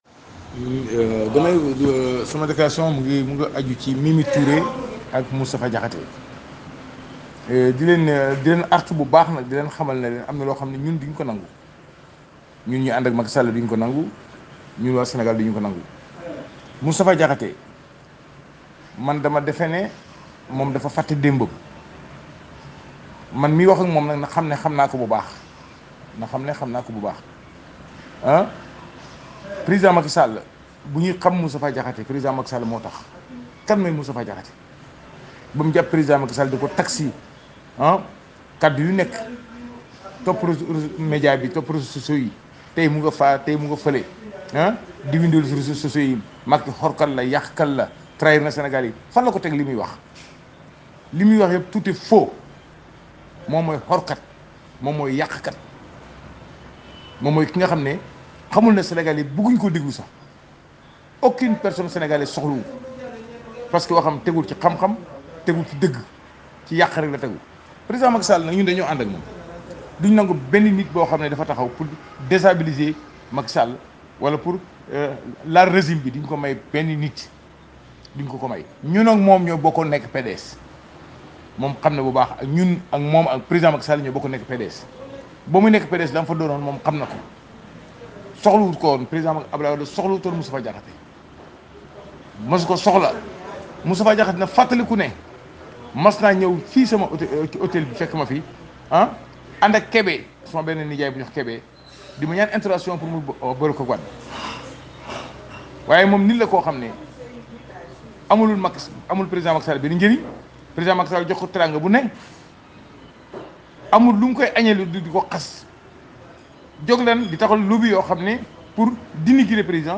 Nous vous laisserons, néanmoins, l’opportunité d’écouter la virulente déclaration de El Malick Guèye contre l’ancien député Moustapha Diakhaté et la déclaration de guerre à l’endroit de Mimi Touré.